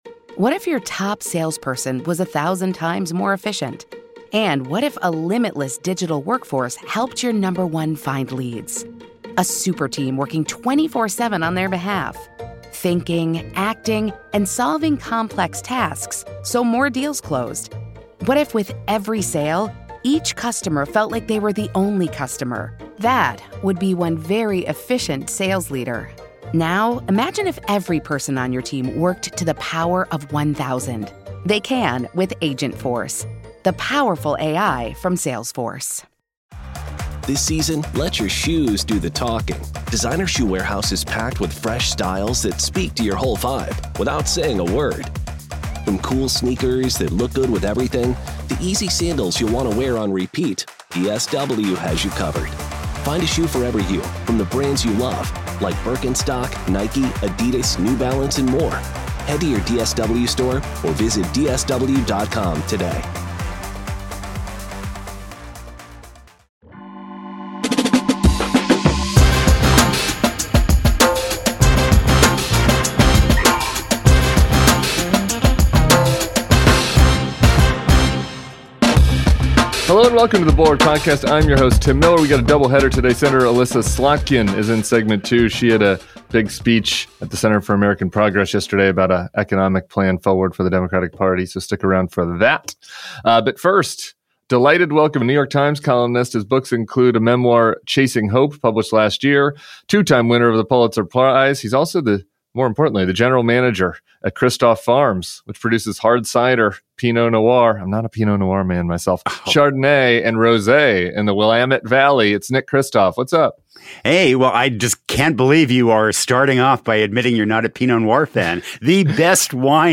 Nick Kristof and Sen. Elissa Slotkin join Tim Miller for the weekend pod.